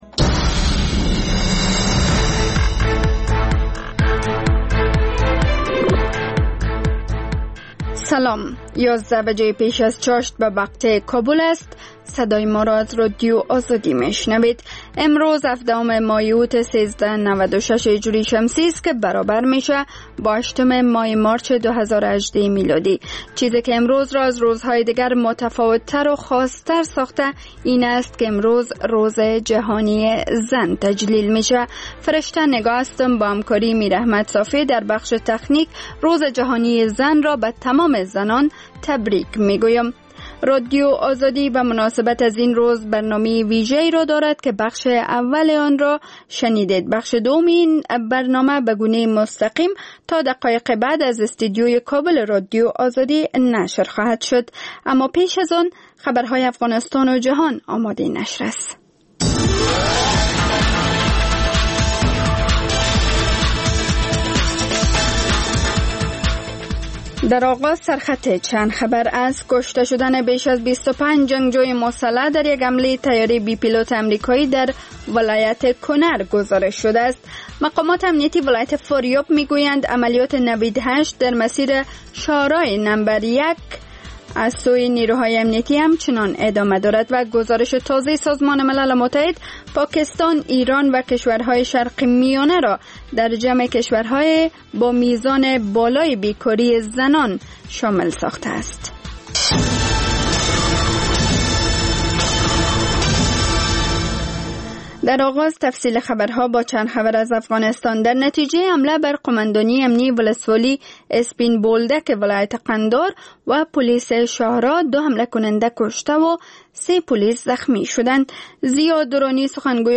خبر ها و گزارش‌ها، سرود و سخن